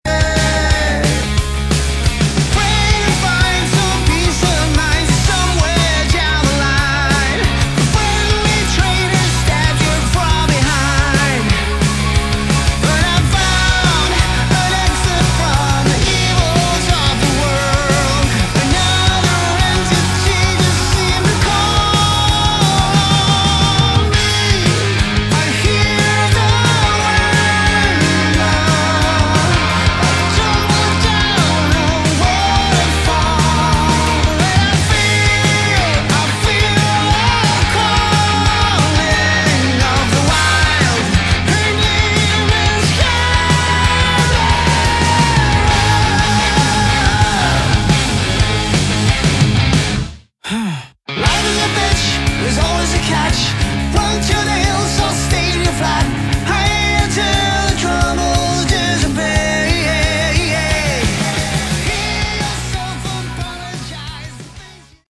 Category: Melodic Hard Rock
guitars
bass, vocals
drums